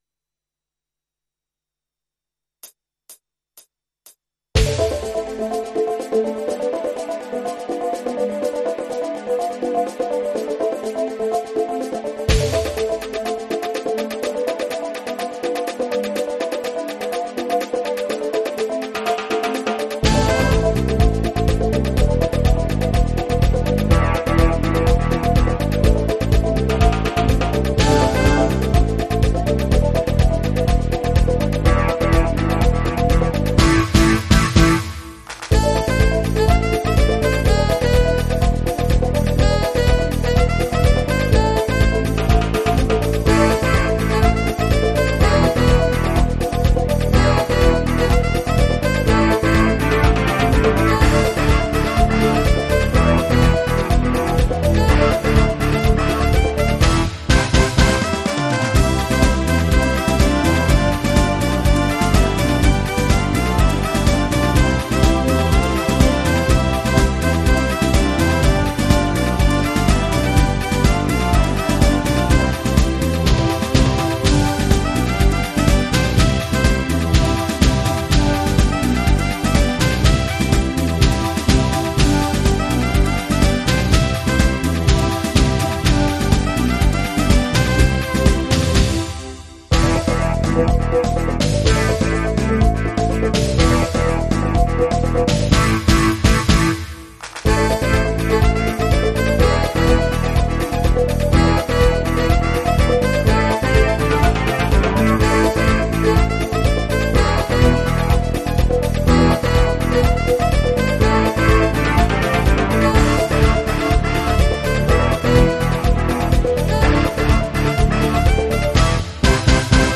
version instrumentale multipistes